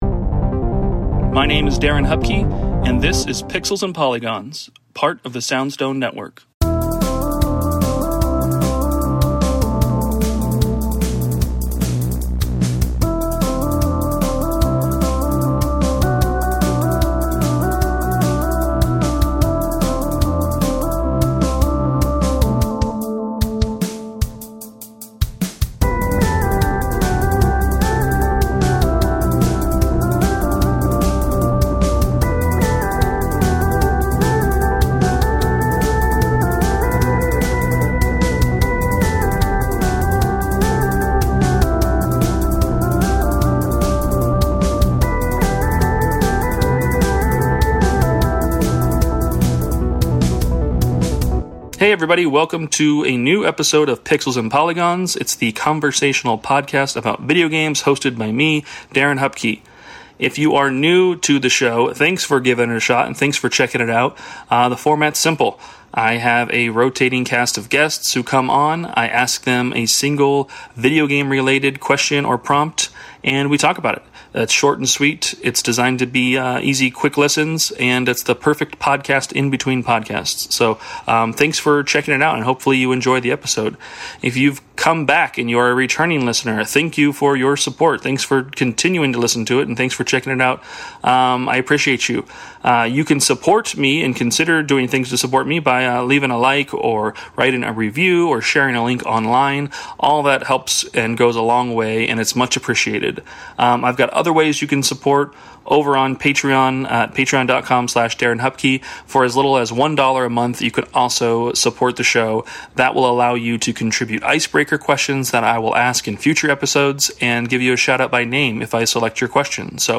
It features a rotating cast of guests each week and captures a conversation on a set gaming topic. Each episode starts with an introduction of the guest and an icebreaker; then, we answer a single gaming question.